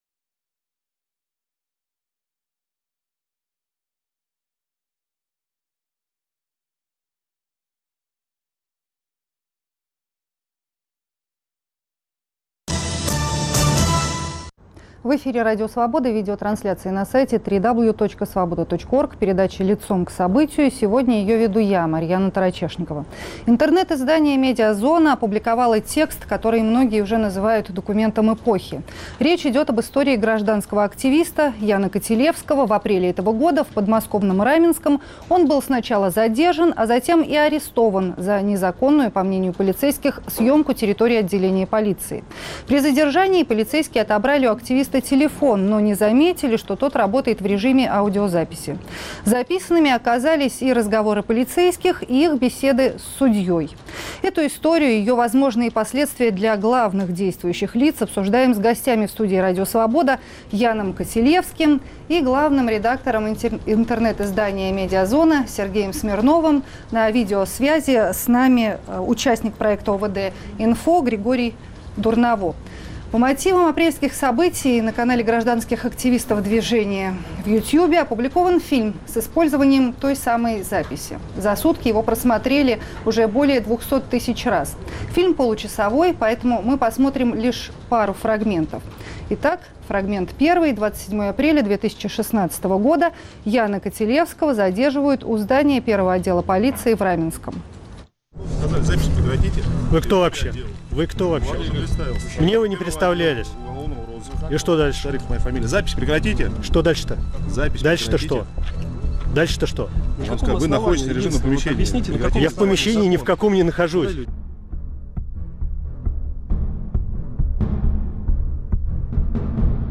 В сети опубликованы разговоры полицейских и судьи, записанные на случайно забытый диктофон. Эту историю и ее возможные последствия для главных действующих лиц обсуждаем с гостями в студи Радио Свобода